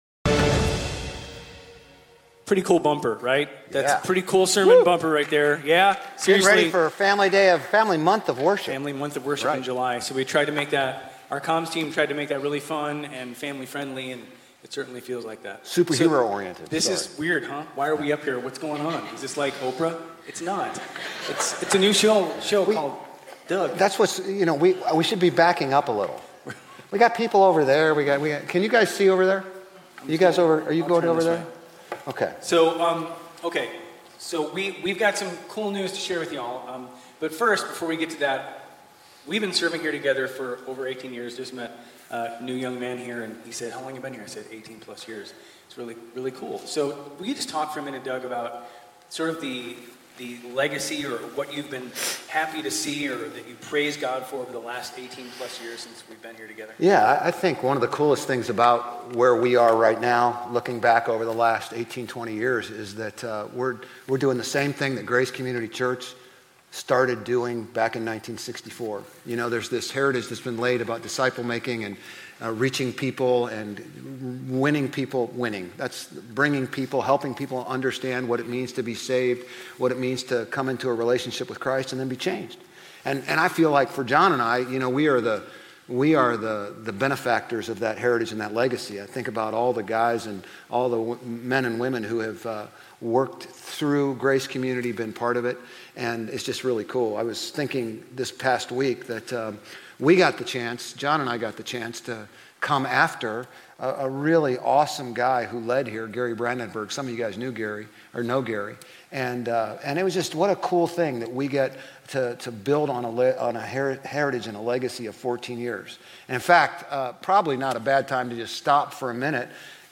Grace Community Church Old Jacksonville Campus Sermons 6_29 Old Jacksonville Campus Jul 01 2025 | 00:33:45 1x 00:00 / 00:33:45 Subscribe Share RSS Feed Share Link Embed